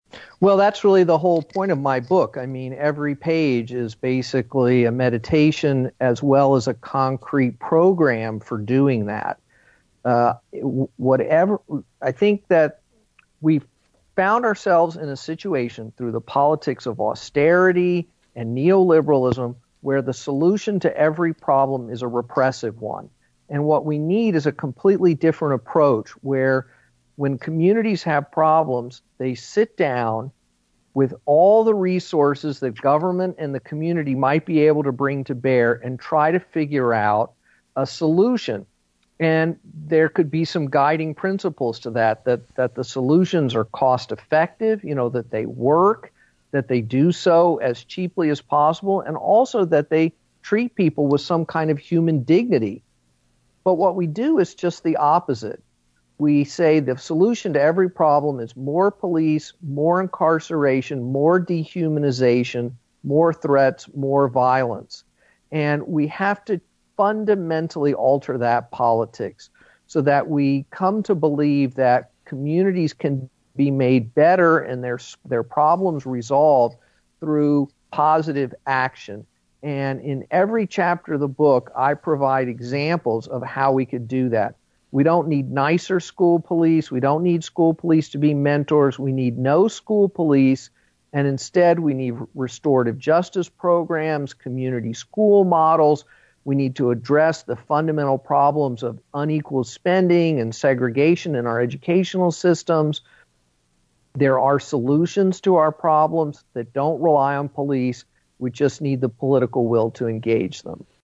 In-Depth Interview: Two Authors Offer Sharp Criticism of US Policing, and Police Reform